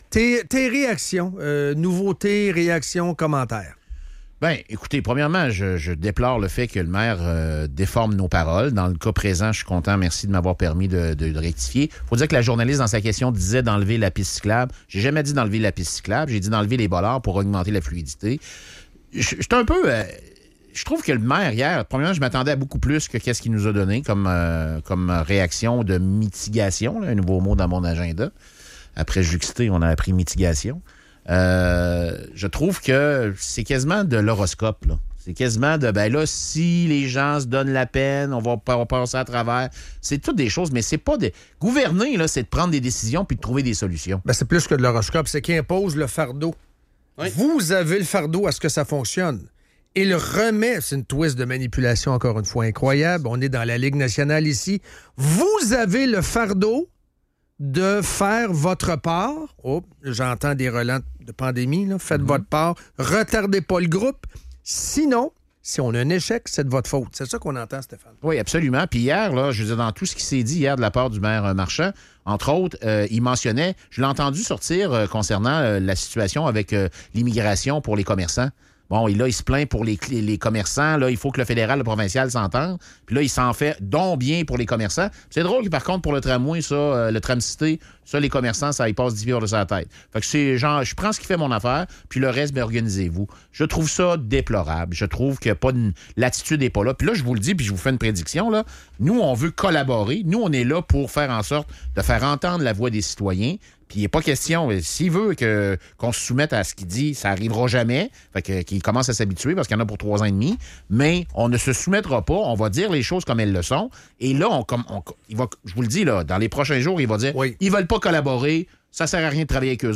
L'auditeur exprime son désarroi face aux conséquences du projet de tramway à Québec, affirmant que cela rendra l'accès au centre-ville trop compliqué. Les inquiétudes se multiplient, notamment pour les commerçants et les parents d'élèves, qui craignent des difficultés d'accès durant les travaux.